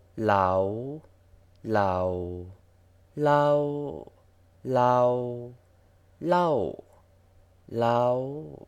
치앙마이 방언에는 6개의 음운론적 톤이 있다.[15][16] 6개의 음운론적 톤은 다음과 같다: 저음 상승, 저음 하강, 성문 폐쇄를 동반하는 고음 수평, 중음 수평, 고음 하강, 고음 상승.